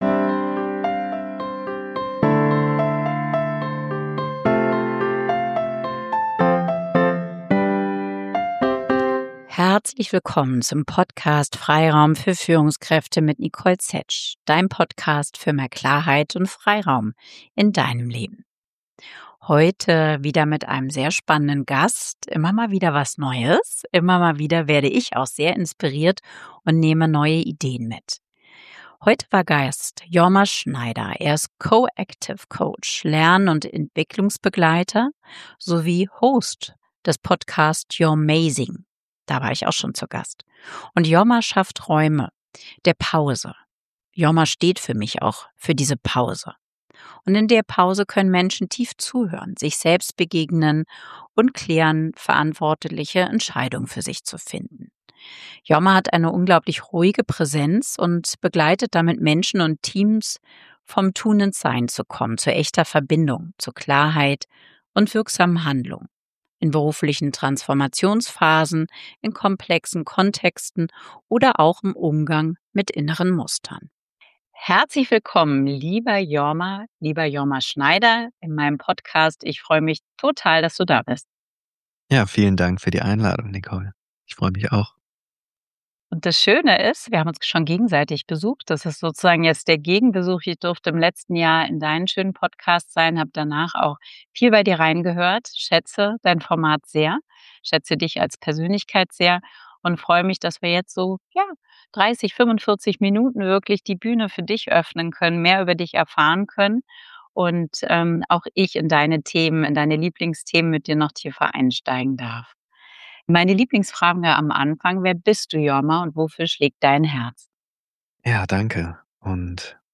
In unserem Gespräch sprechen wir darüber, was es bedeutet, bewusst innezuhalten, warum echte Gespräche so selten geworden sind und wie es gelingen kann, auch in digitalen oder hybriden Kontexten Verbindung herzustellen.